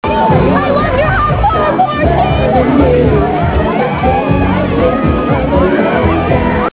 Pop
Comment: boy band